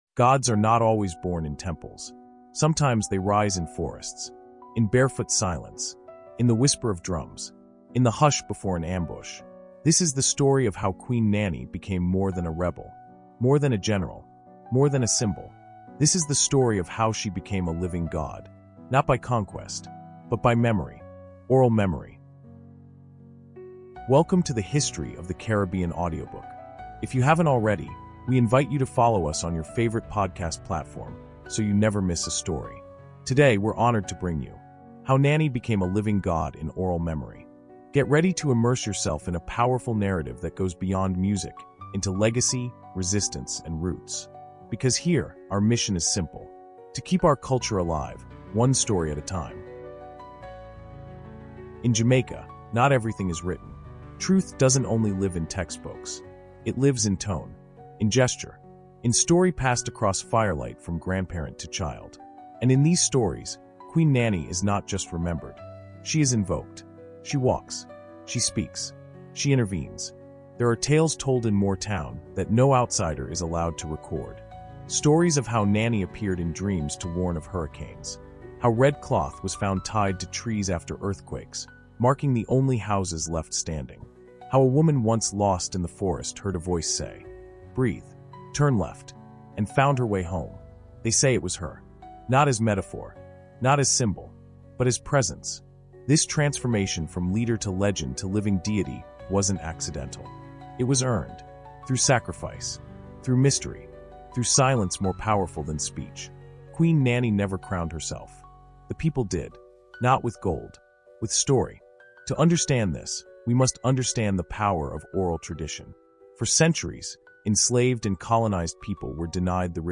In this soulful 20-minute audio insight, we explore how Queen Nanny transcended history to become a living god through the oral memory of her people.